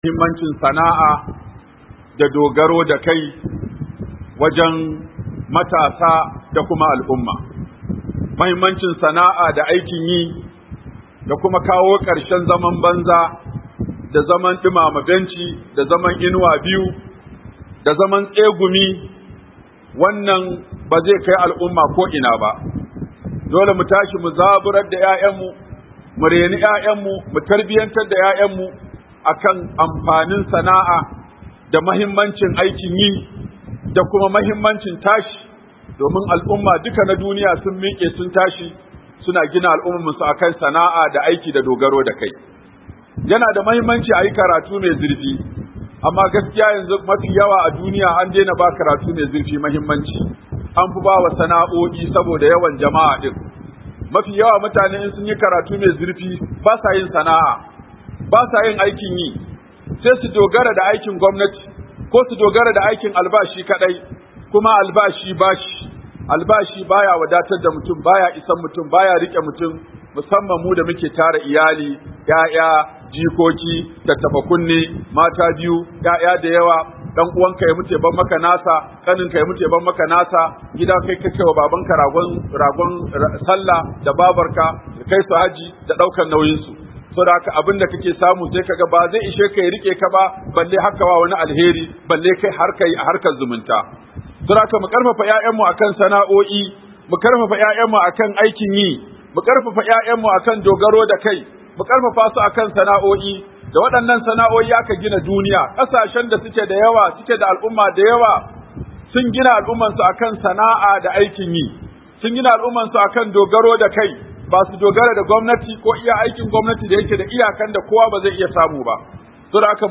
Muhimmancin Sana'a da Dogaro da Kai ga Matasa - Huduba by Sheikh Aminu Ibrahim Daurawa